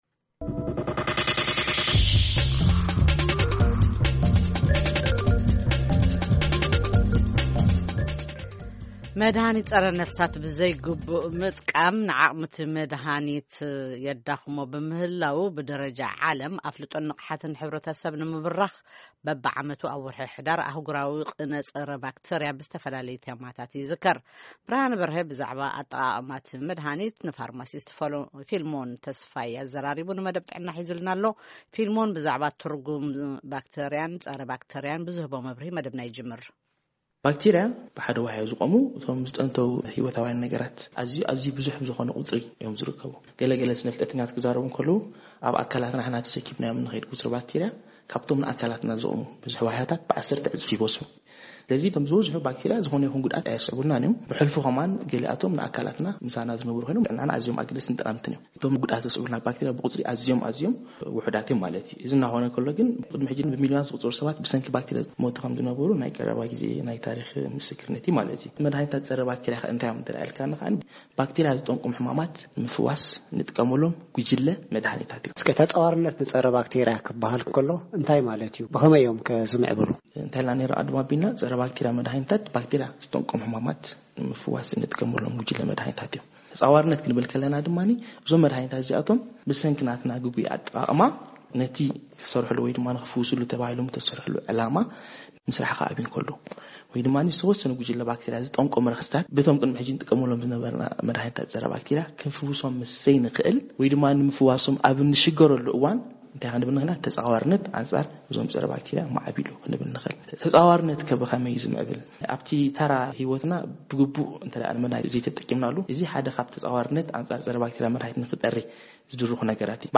ቃለ-መጠይቕ